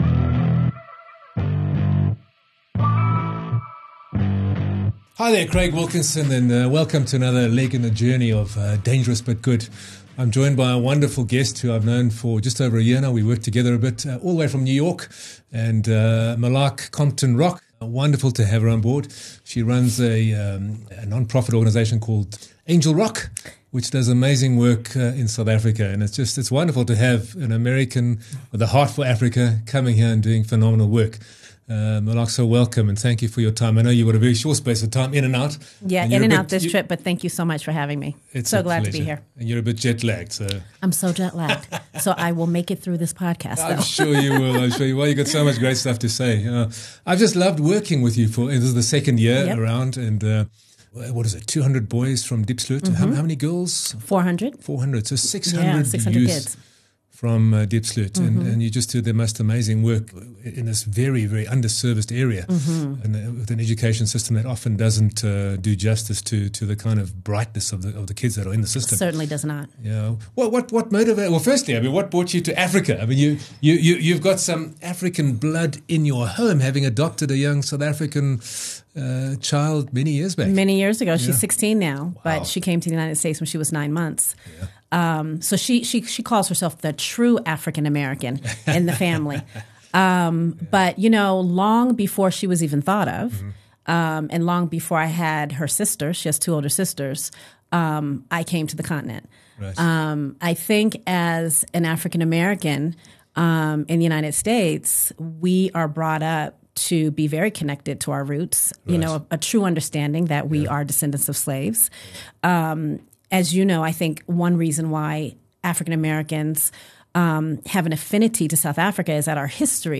Be inspired by this moving conversation to play your role in modelling the way for the next generation to build a safe and prosperous future for us all.